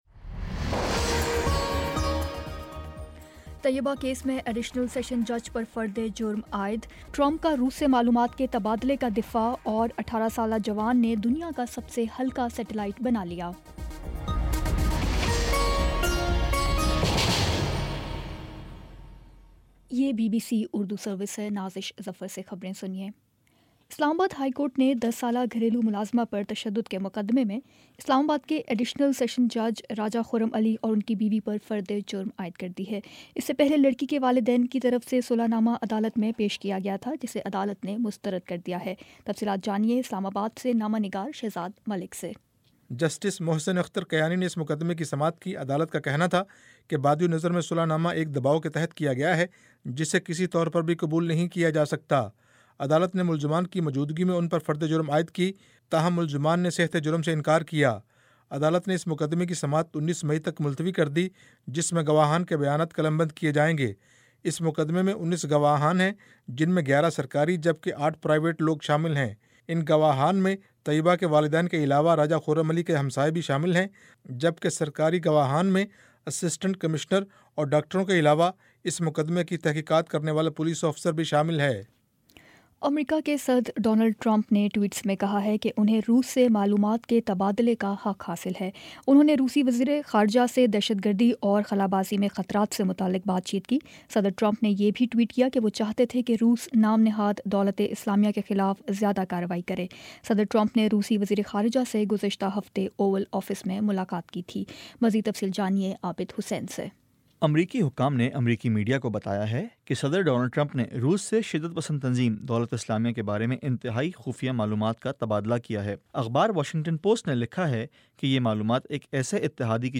مئی 16 : شام چھ بجے کا نیوز بُلیٹن